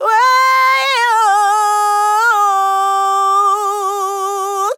TEN VOCAL FILL 10 Sample
Categories: Vocals Tags: dry, english, female, fill, sample, TEN VOCAL FILL, Tension
POLI-VOCAL-Fills-100bpm-A-10.wav